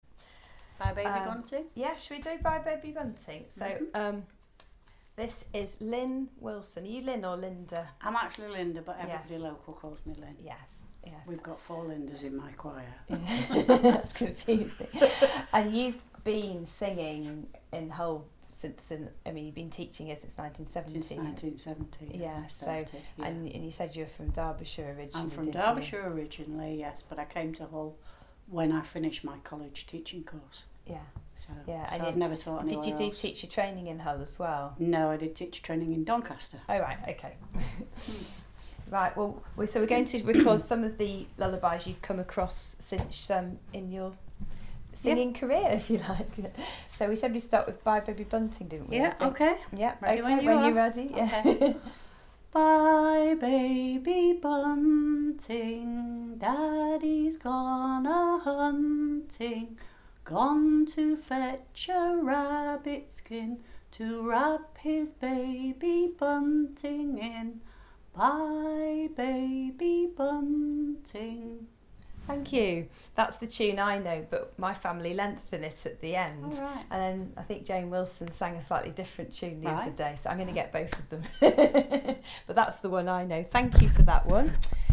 Lullaby_recording Public